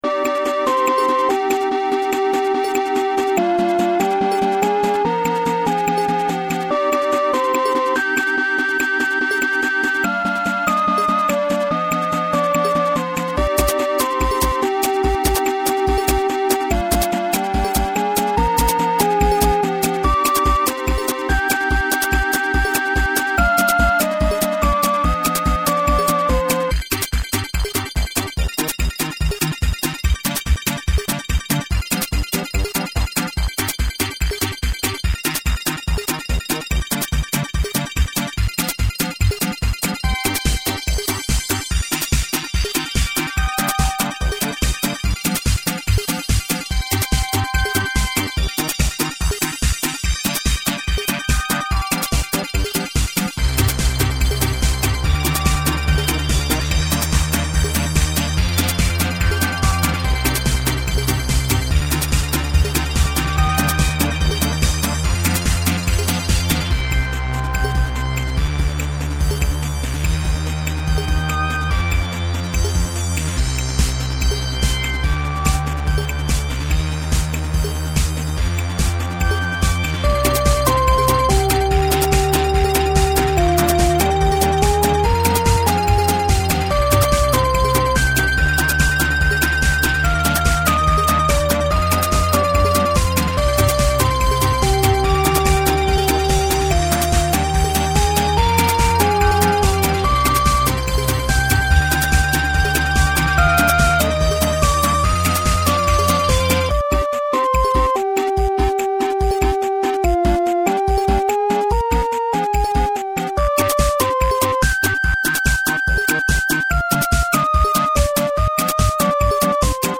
Vrij experimenteel.
Lol, ik hoor die derde nu voor het eerst, dik melig.